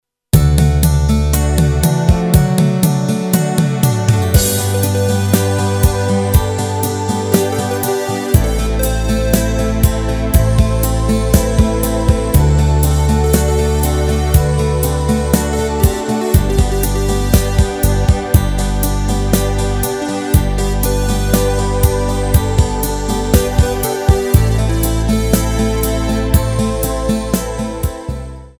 HUDEBNÍ PODKLADY V AUDIO A VIDEO SOUBORECH